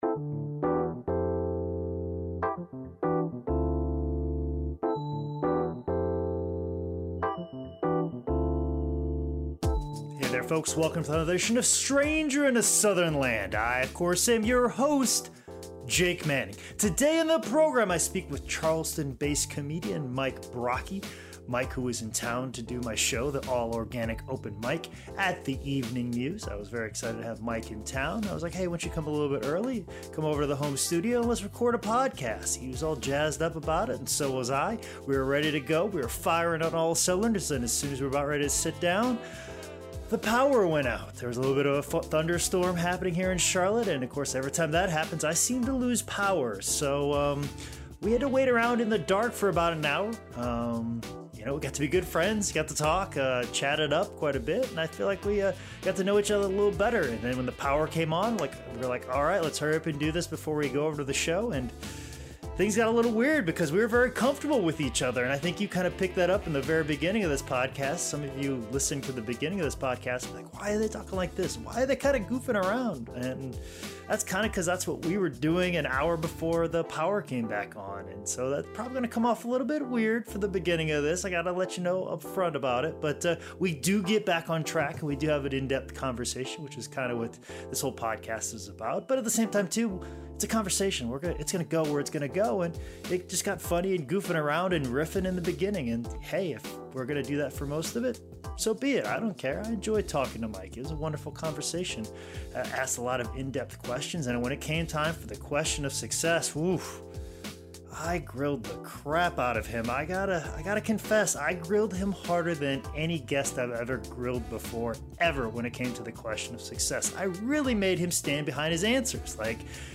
record an amazing podcast in the home studio